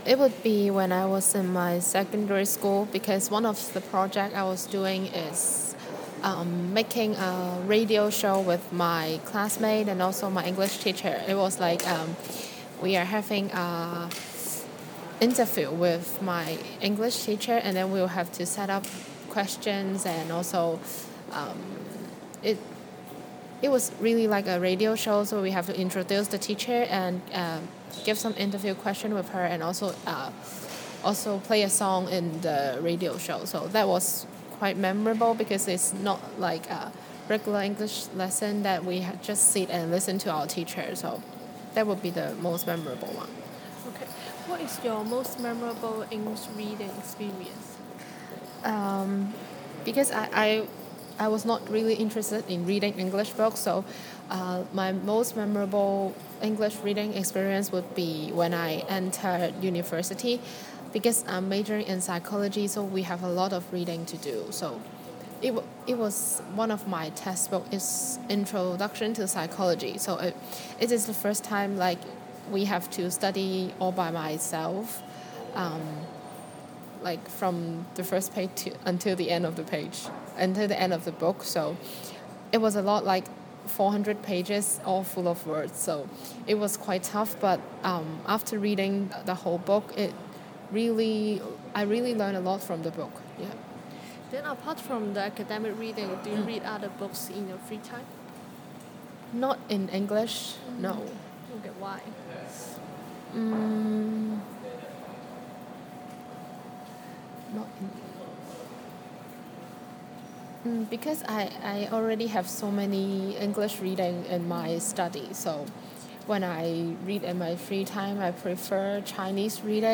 Subcategory: Activity, Non-fiction, Reading, Speech